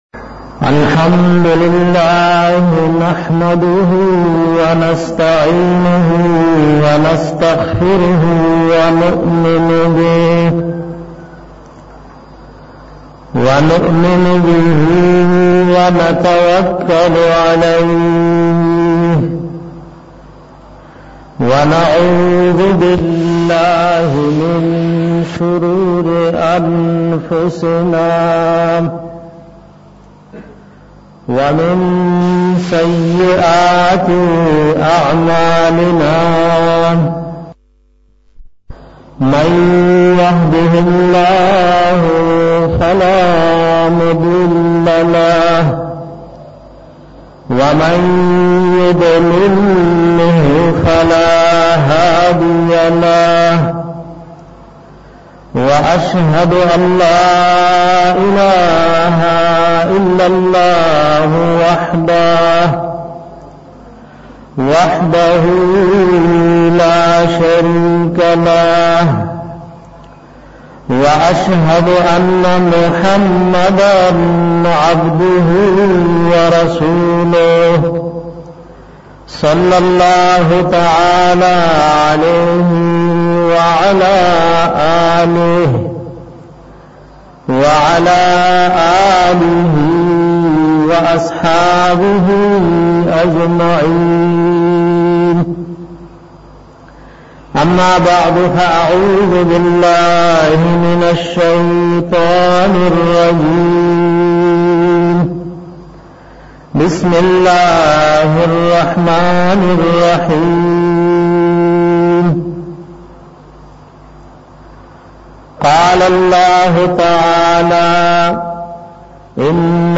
fazilat da ilam ao uloma pa bara ka bayan